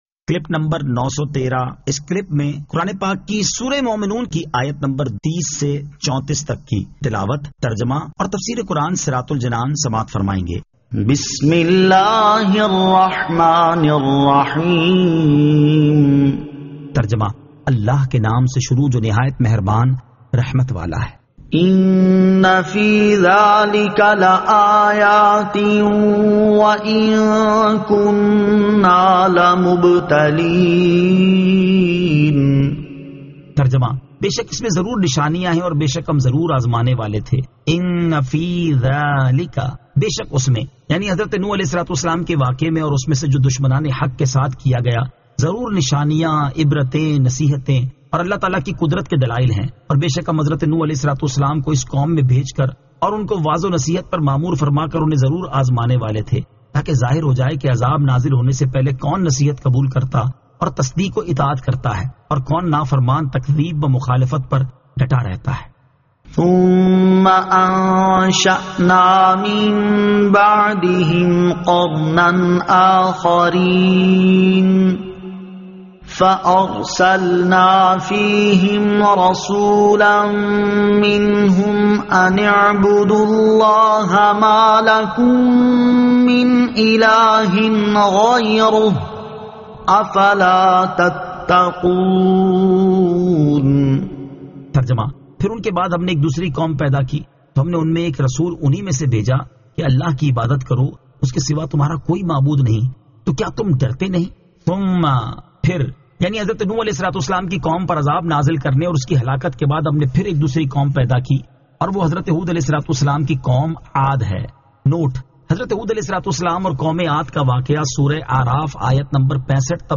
Surah Al-Mu'minun 30 To 34 Tilawat , Tarjama , Tafseer